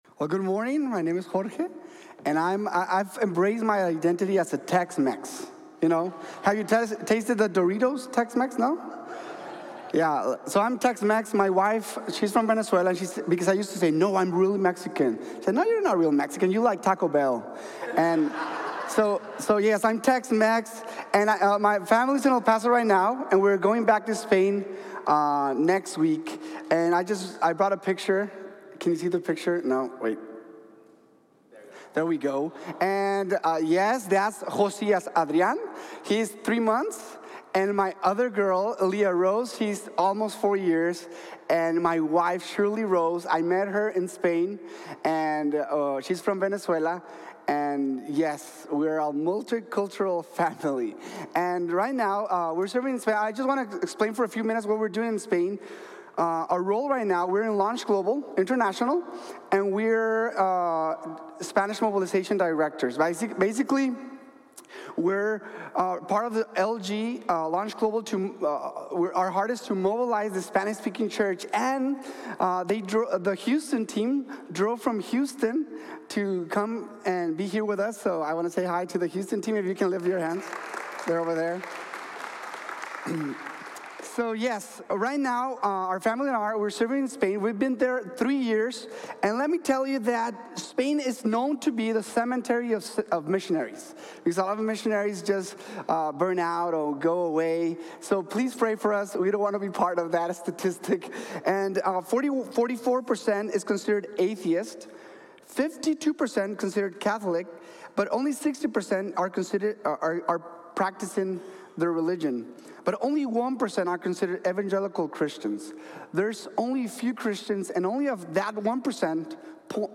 The importance of the church that sends | Sermon | Grace Bible Church